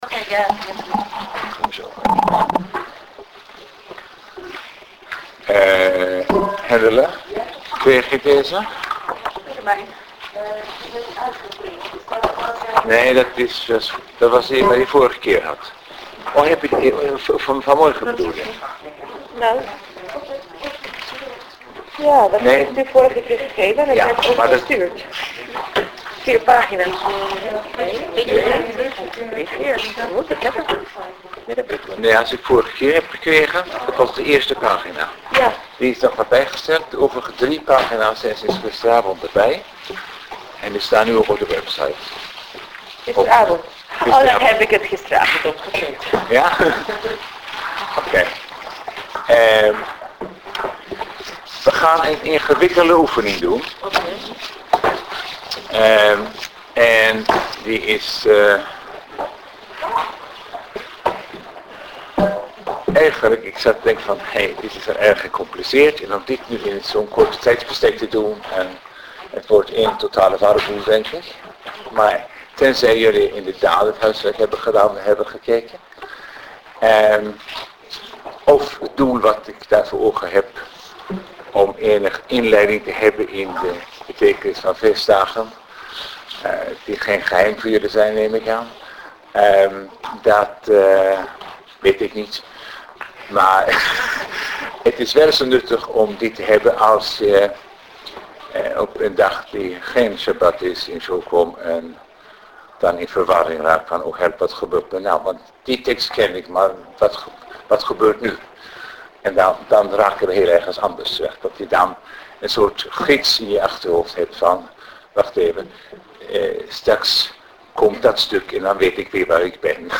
De lezingen tijdens deze 20 studiedagen zijn op video opgenomen.